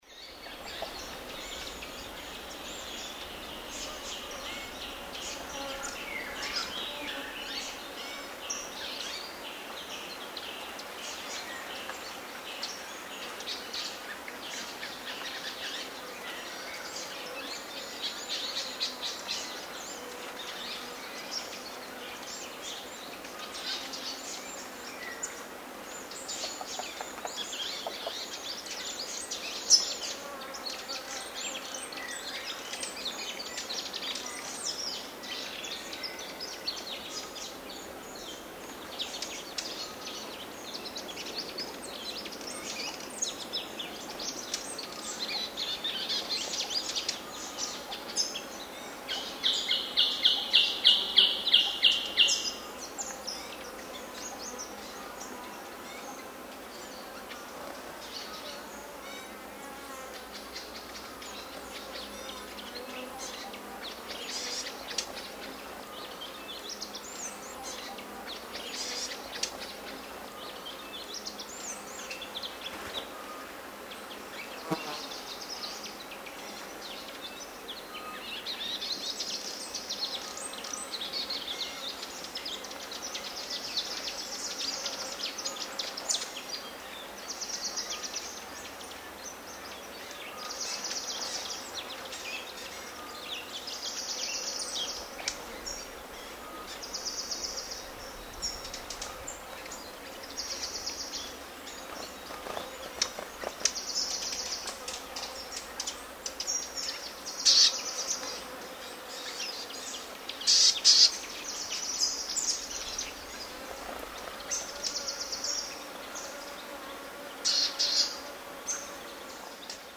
Звуки ночного леса
Звуки южноамериканских джунглей: